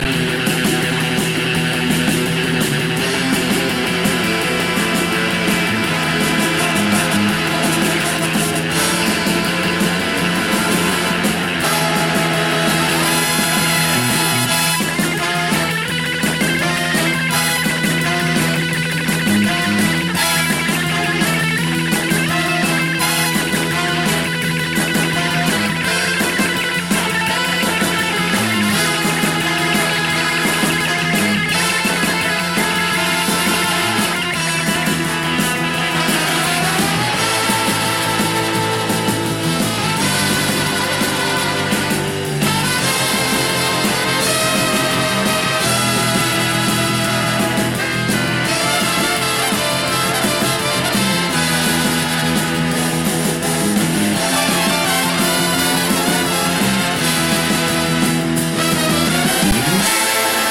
Killer covers of 2 important sound track moments.